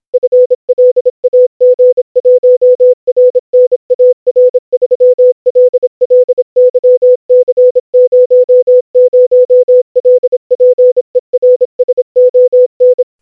morse code part only for you to try.
25w500f means 25 words per min 500 frequency.